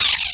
pain2.wav